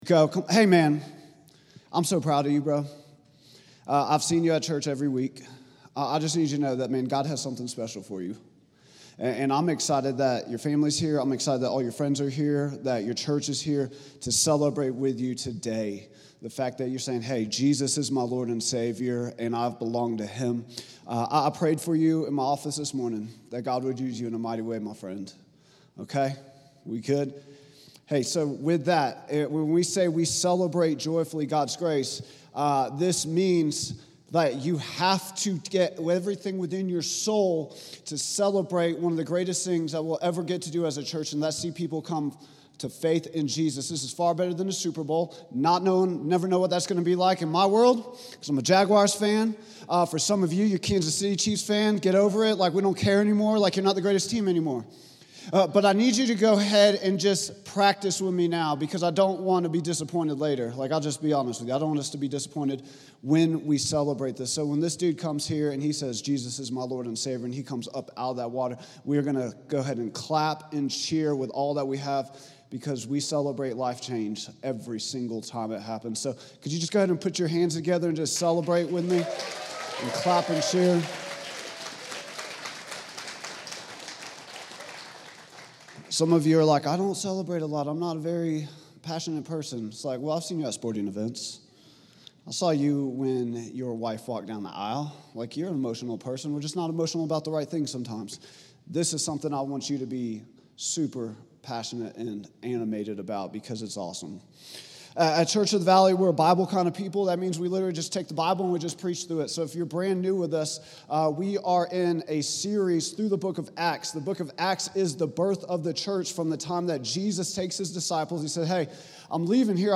Vision & Values Meet Our Team Statement of Faith Sermons Contact Us Give Those People | Acts 10:1-33 October 26, 2025